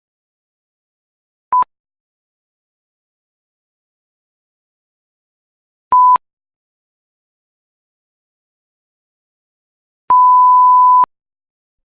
جلوه های صوتی
دانلود صدای بوق سانسور 2 از ساعد نیوز با لینک مستقیم و کیفیت بالا